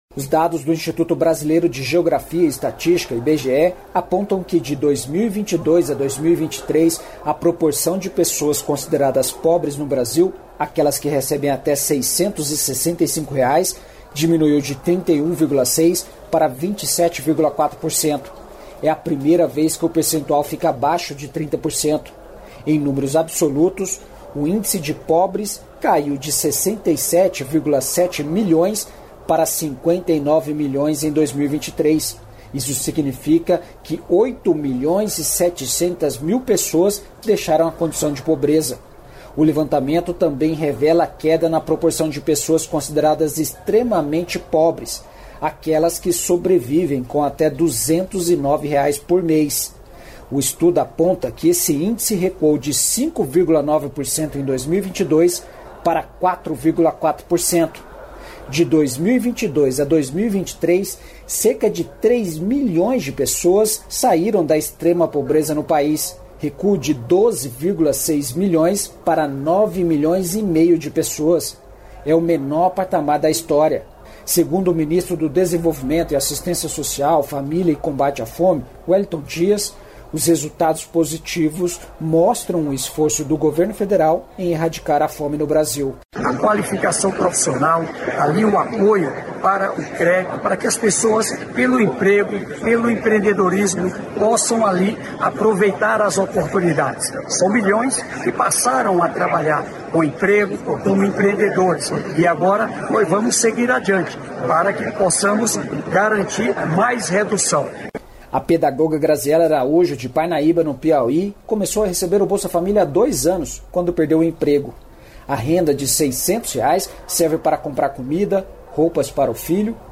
A reunião resultou em uma declaração conjunta com diversos pontos como taxação dos super-ricos e inclusão dos países pobres. O ministro da Fazenda Fernando Haddad falou sobre os acordos.